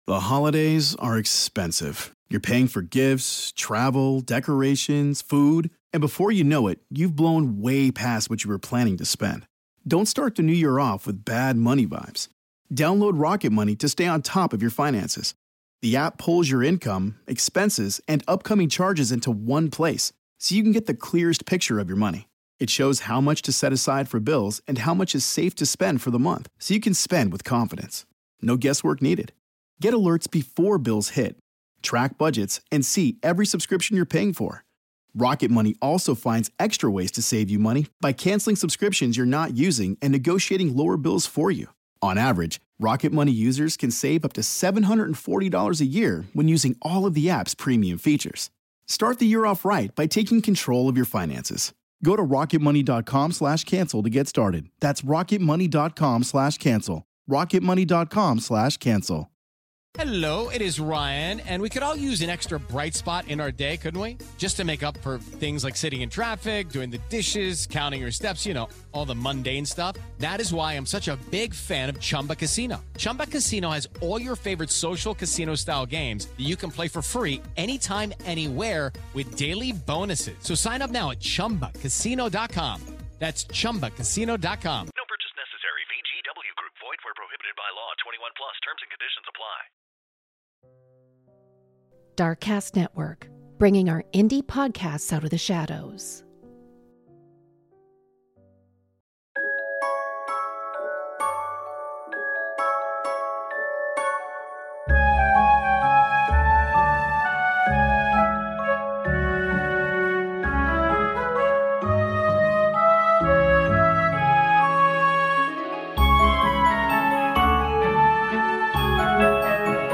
The episode finishes with a Running Water spell written by the host to the calming sound of a running stream.